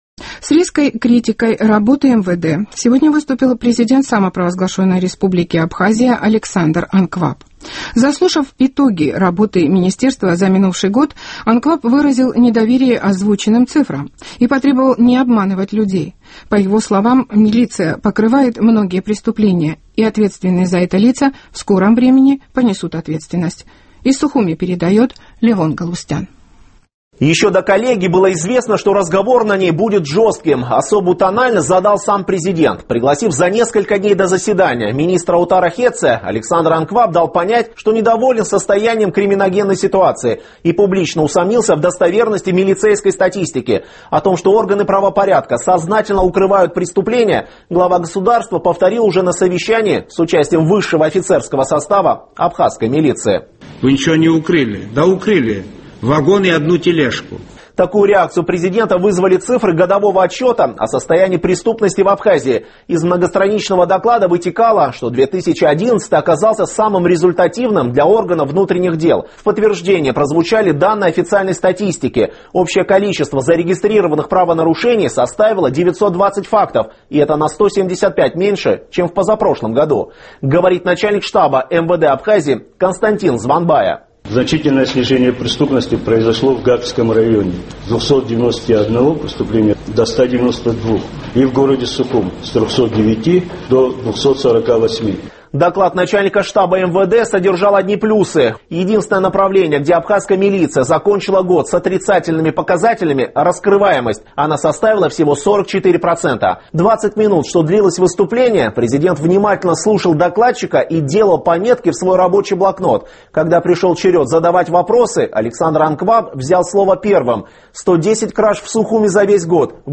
С резкой критикой работы МВД сегодня выступил президент Абхазии Александр Анкваб.